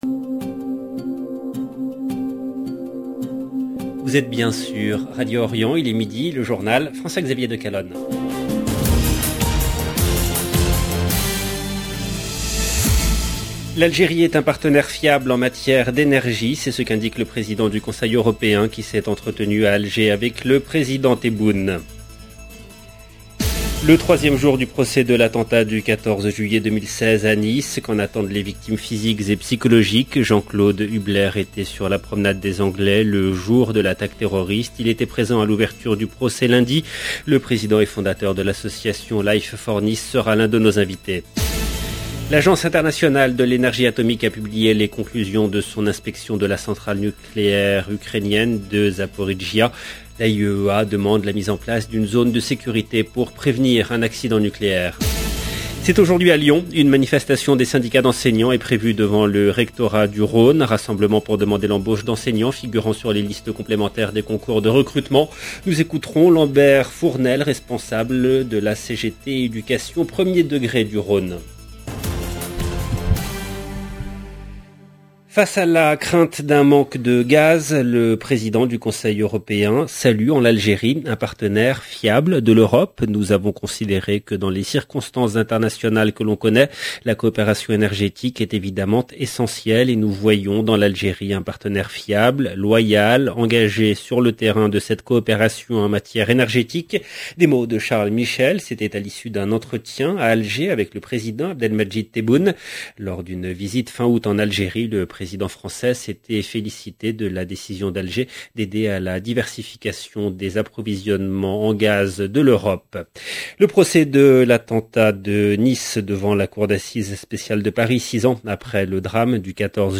EDITION DU JOURNAL DE 12 H EN LANGUE FRANCAISE DU 7/9/2022